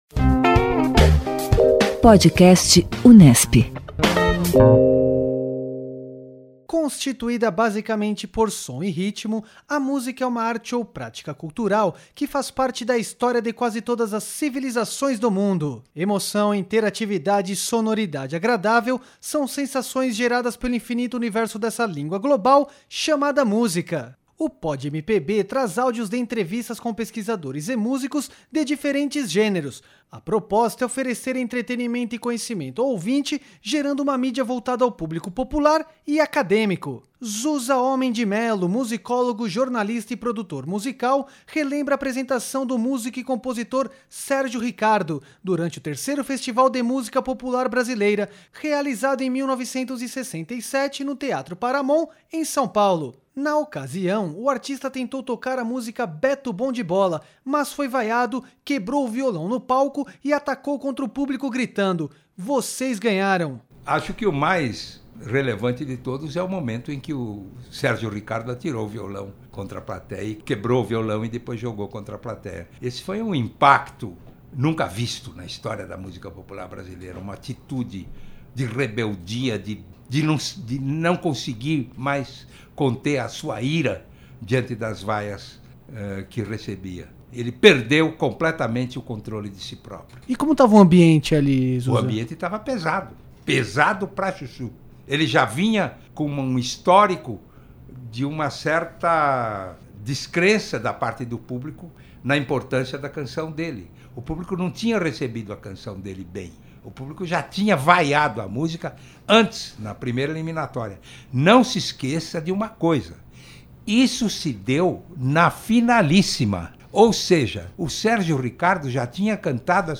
Na ocasião, o artista tentou tocar a música Beto bom de bola, mas foi vaiado, quebrou o violão no palco e atacou contra o público gritando: Vocês ganharam! O Podcast Unesp conta o áudio gravado durante o evento.
O PodMPB traz áudios de entrevistas com pesquisadores e músicos de diferentes gêneros, com a proposta de oferecer entretenimento e conhecimento ao ouvinte.